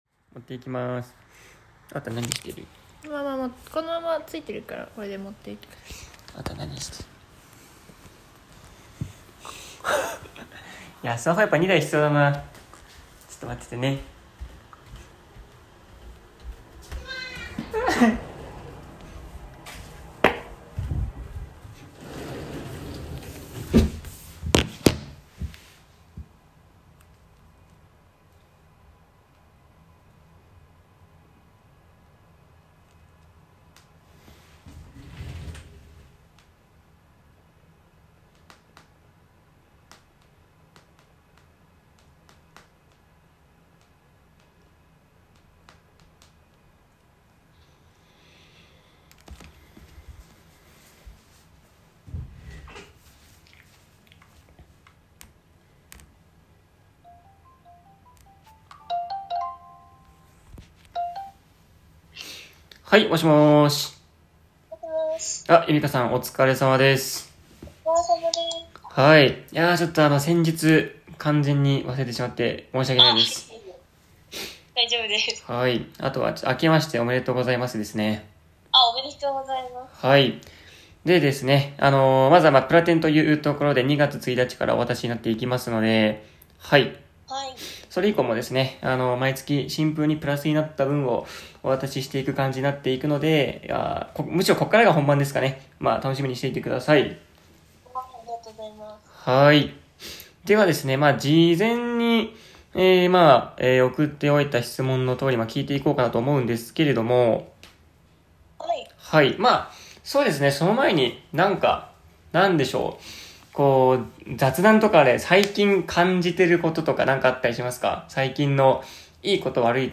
テンポよく最後まで見れるように 1.5倍速に編集してありますので ぜひ見てください。